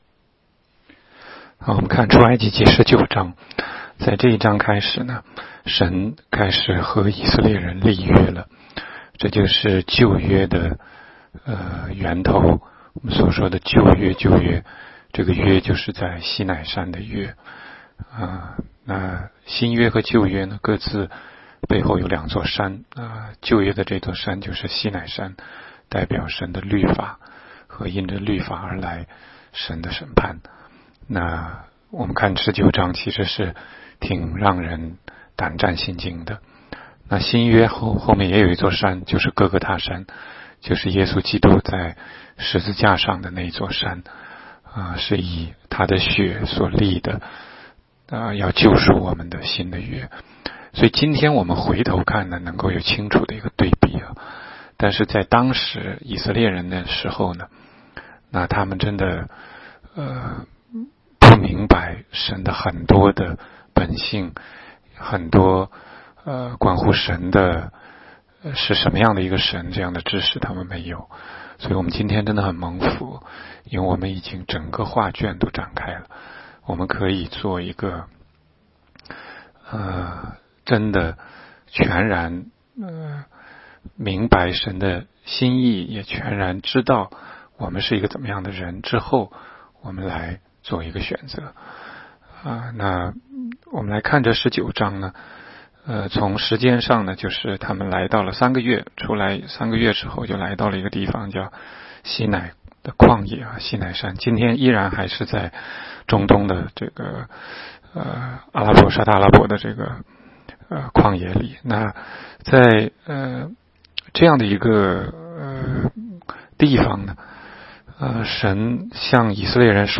16街讲道录音 - 每日读经-《出埃及记》19章